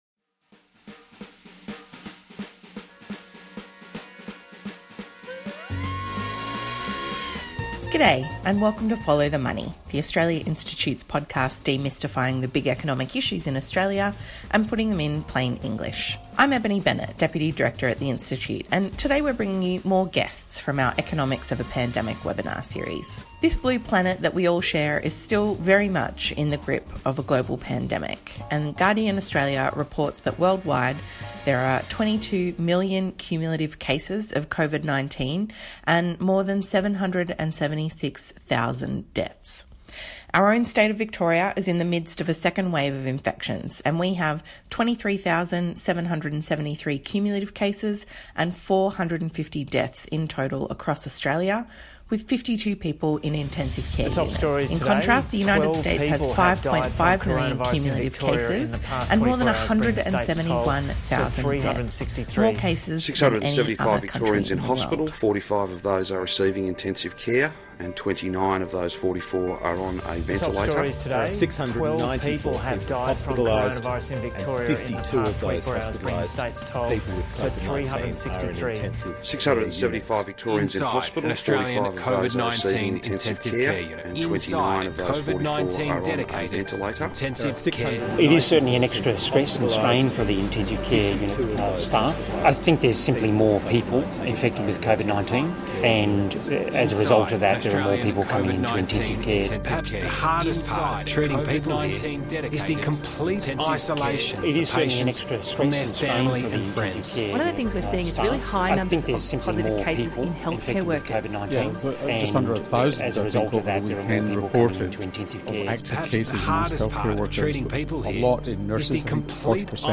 We are joined by three experienced ICU clinicians who are part of the COVID-19 Critical Care Consortium Study — the first study of its kind in the world.